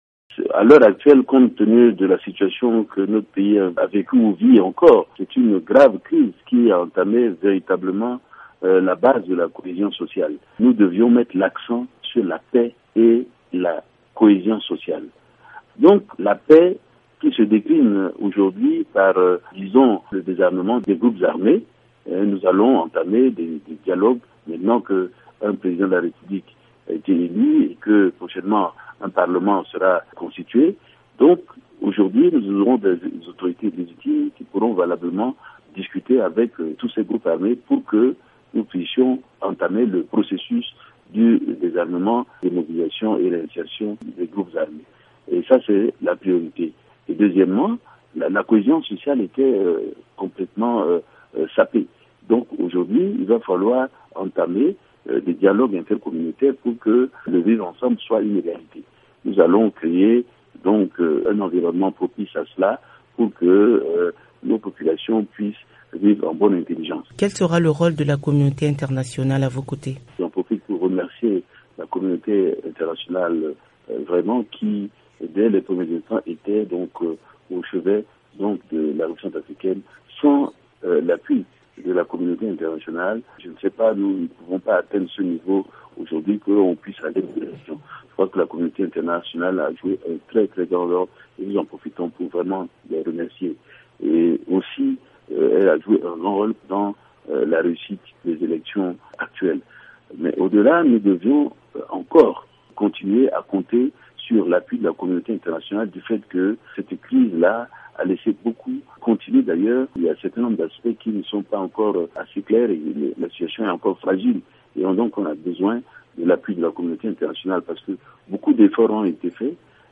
Faustin Archange Touadéra, le nouveau président élu en Centrafrique indique, dans une interview exclusive à VOA Afrique, qu’il fait de la cohésion et de la paix sa priorité pour remettre le pays sur les rails.